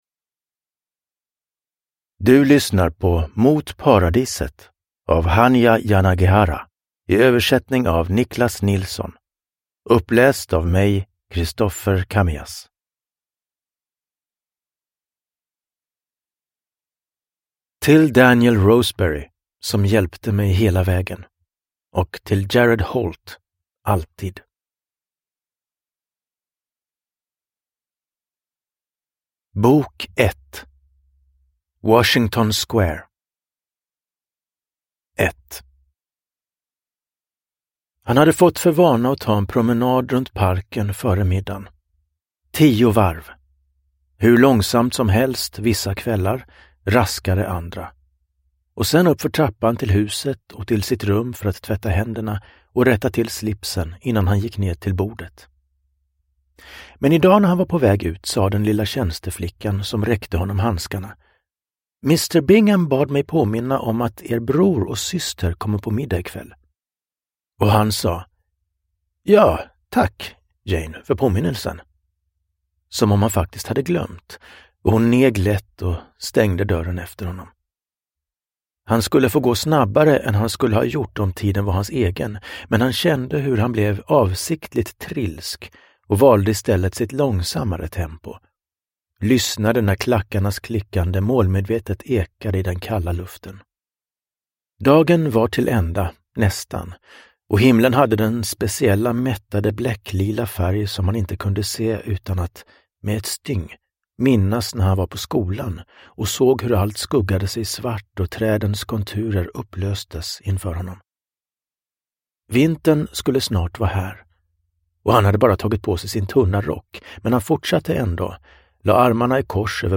Mot paradiset – Ljudbok – Laddas ner